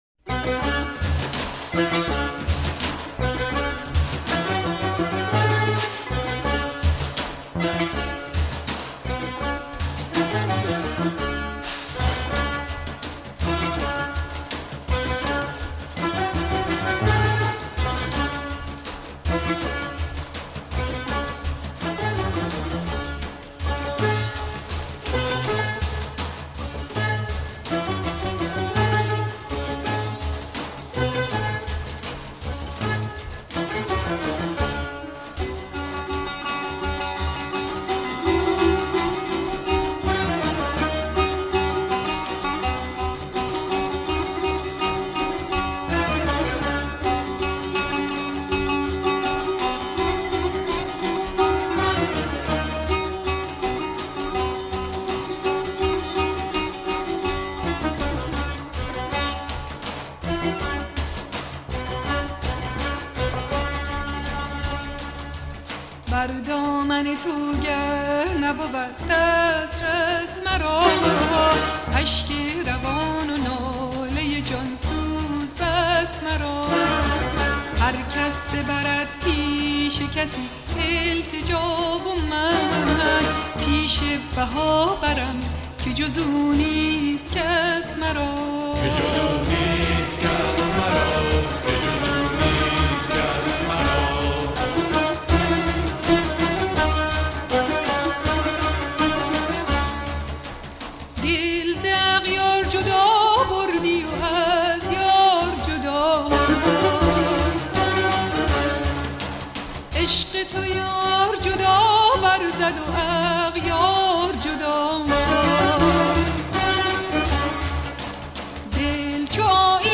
سرود - شماره 3 | تعالیم و عقاید آئین بهائی
مجموعه ای از مناجات ها و اشعار بهائی (سنتّی)